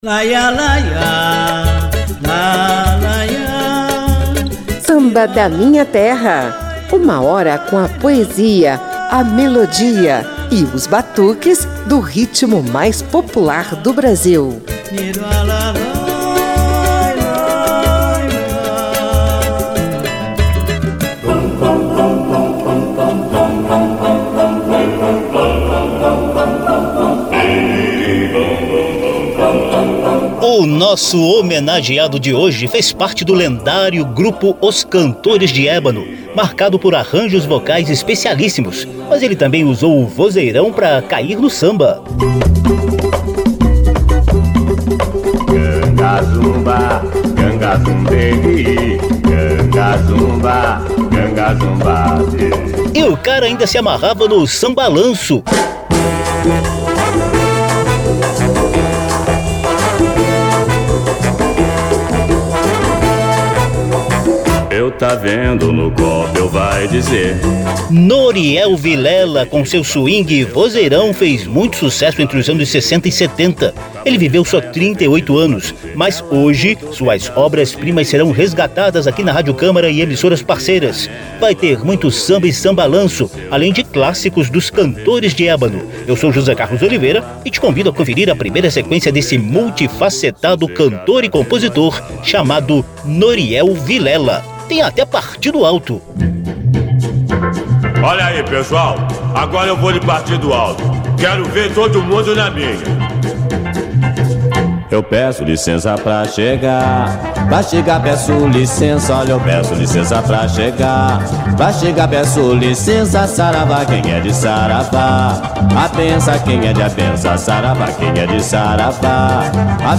Multifacetado, ele emprestou o vozeirão e o suingue para variados estilos musicais, como sambalanço, partido alto e samba clássico.